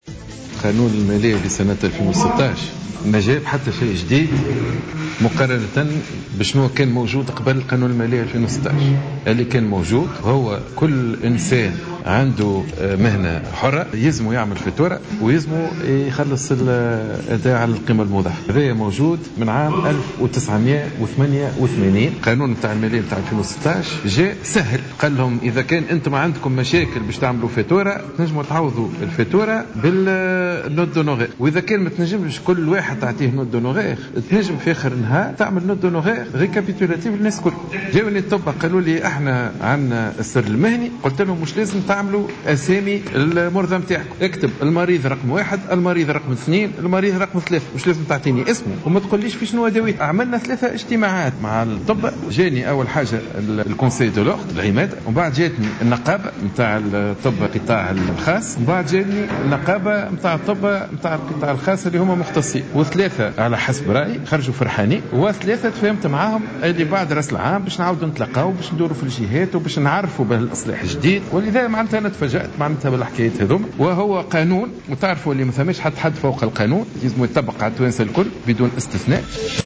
وأوضح الوزير على هامش حضوره في أشغال ورشة العمل الخاصة بتقييم السياسيات العُمومية في مجال التشغيل إن قانون المالية لسنة 2016 لم يأتي بالجديد، مضيفا أن القانون يحتّم على كل من يمتهن مهنة حرّة خلاص الأداء على القيمة المضافة.